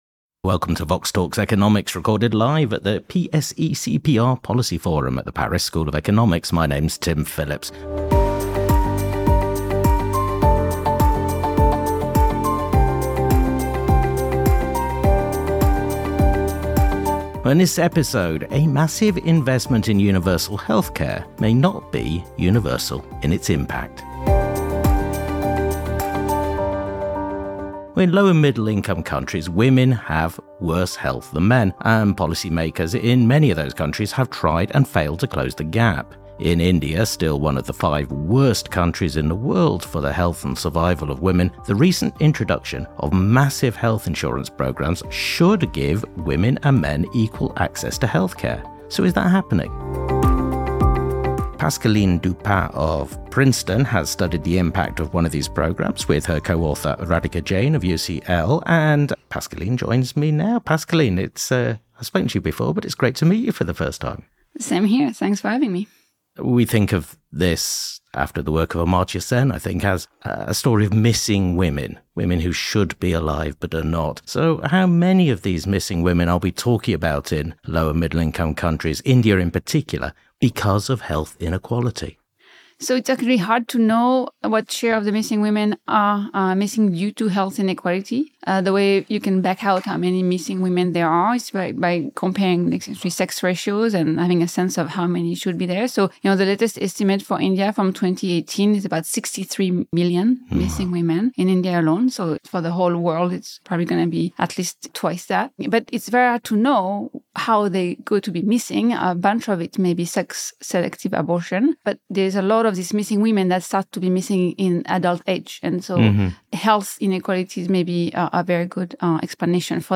Facebook Twitter Headliner Embed Embed Code See more options Recorded live at the PSE-CEPR Policy Forum at the Paris School of Economics. India is still one of the five worst countries in the world for the health and survival of women. The recent introduction of massive health insurance programmes should help close this deadly gender gap.